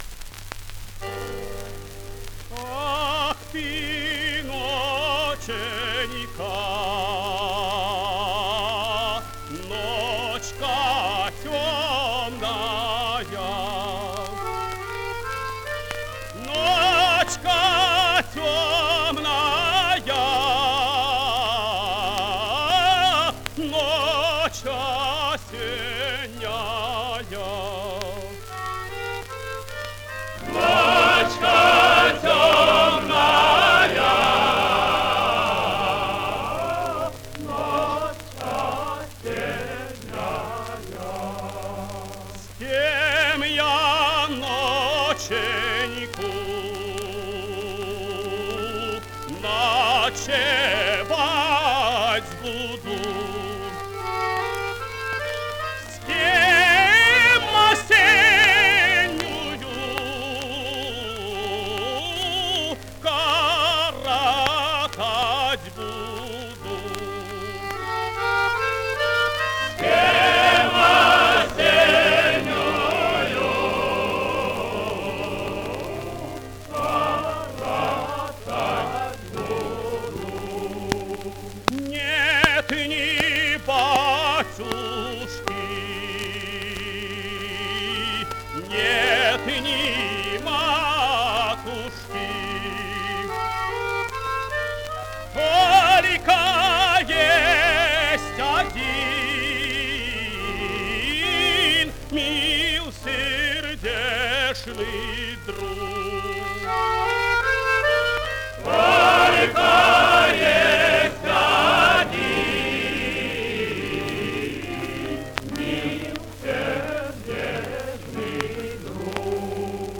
Обладал сильным лирико-драматическим тенором широкого диапазона, мягкого тембра с характерной окраской, драматическим и комедийным талантом.
Русская народная песня «Ах, ты ноченька».
Солист С. Н. Стрельцов.